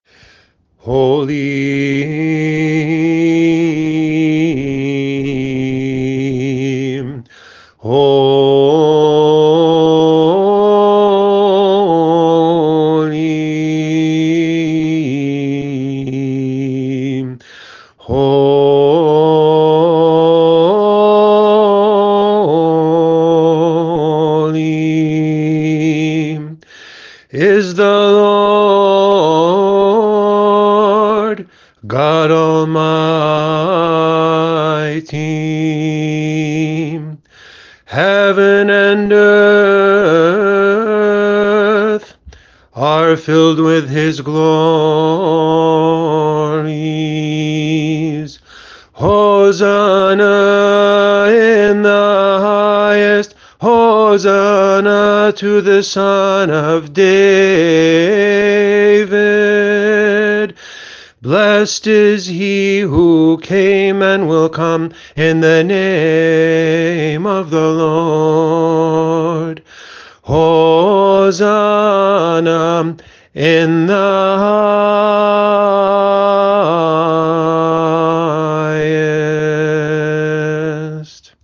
They’re set to the traditional melodies, but in English, with close attention given to the stress of the syllables so it sounds as not-awkward as possible.
Please excuse the quality of my voice.